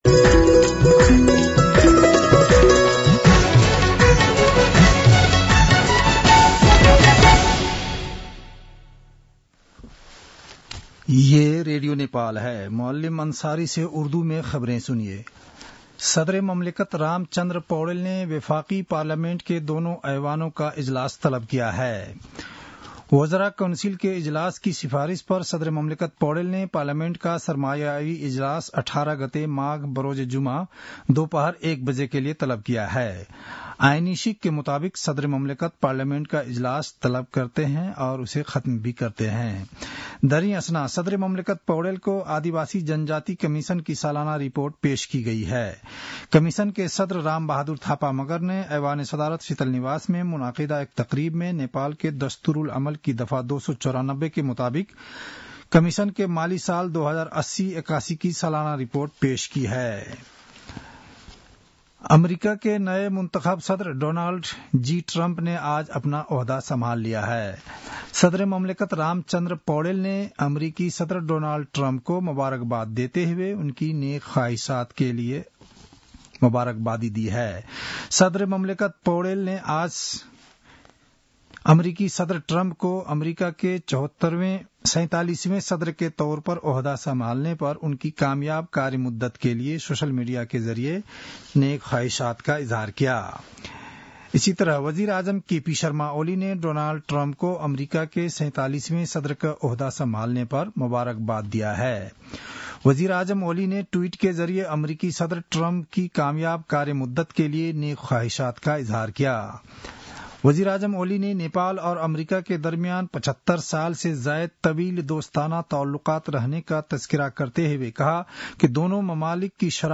उर्दु भाषामा समाचार : ९ माघ , २०८१